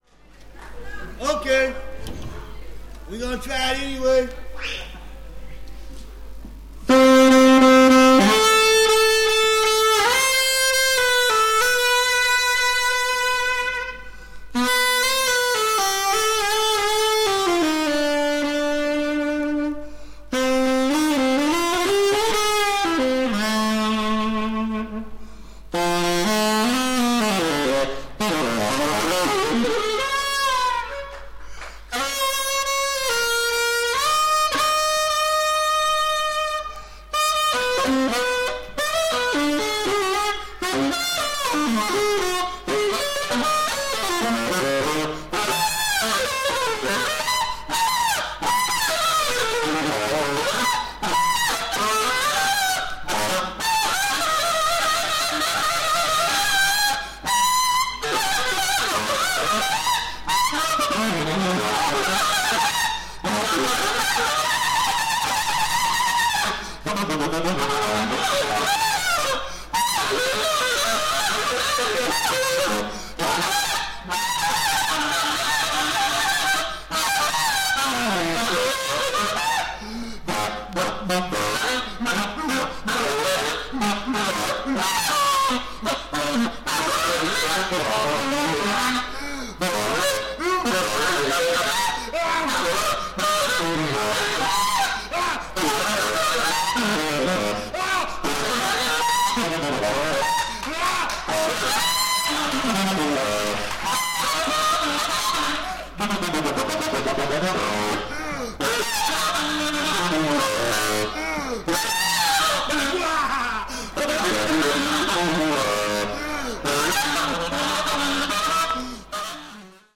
全編、ディープで力感に満ちた素晴らしい演奏です。